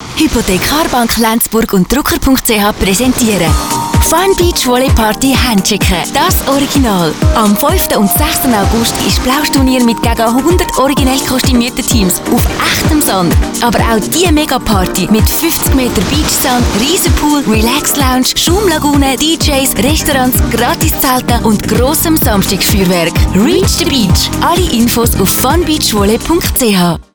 Radio Spot Radio Argovia